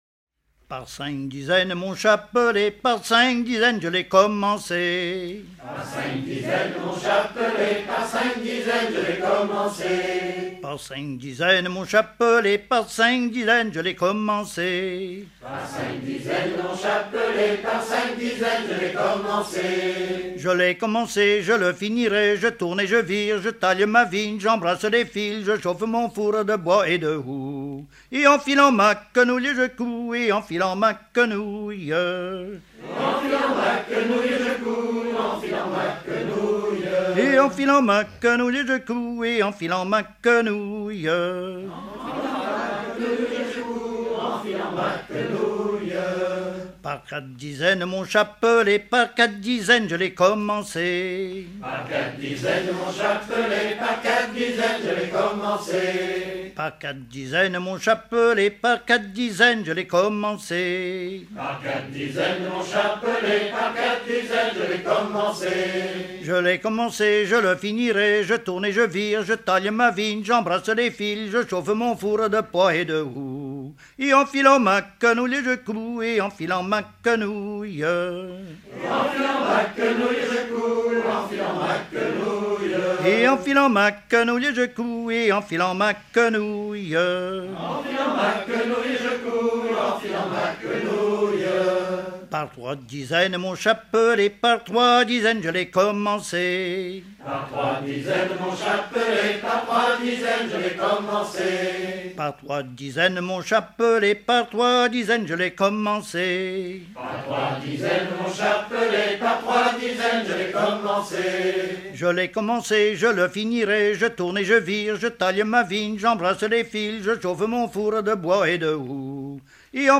Fonction d'après l'analyste gestuel : à marcher
Genre énumérative